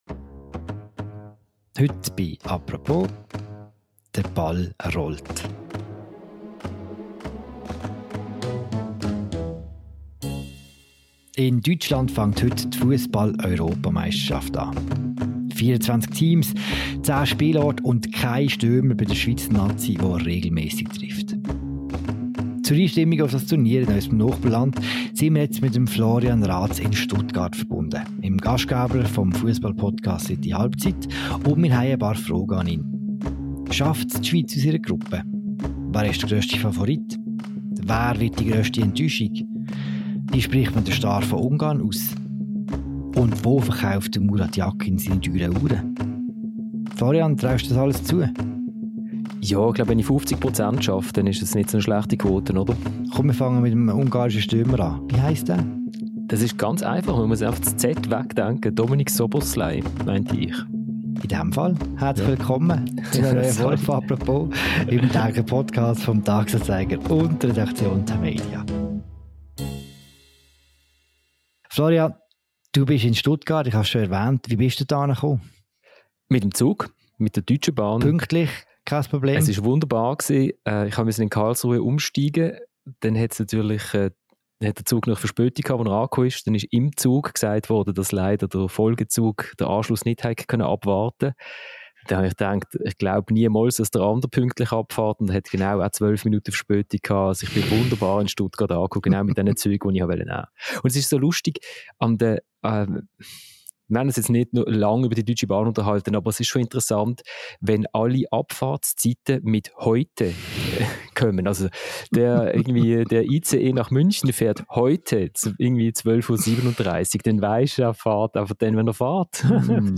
Er ist zu Gast in einer neuen Folge «Apropos», dem täglichen Podcast des «Tages-Anzeigers» und der Redaktion Tamedia.